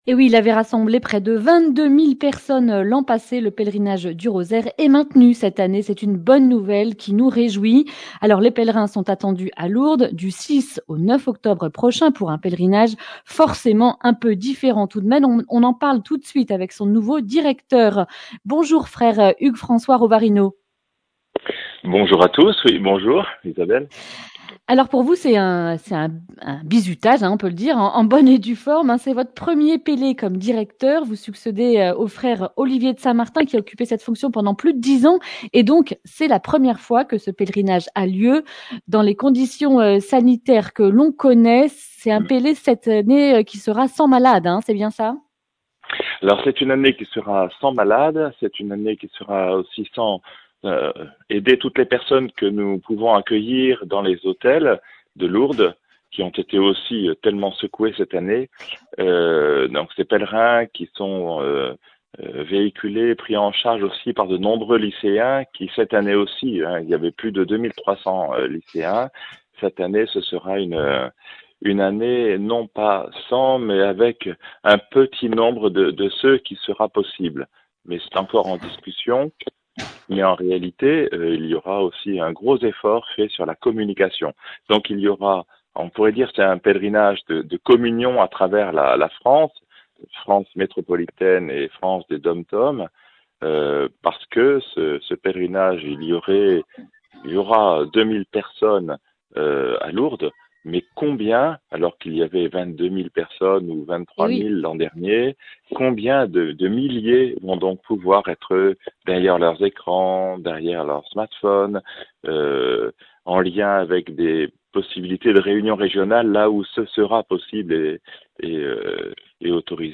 Accueil \ Emissions \ Information \ Régionale \ Le grand entretien \ Le pèlerinage du Rosaire aura bien lieu !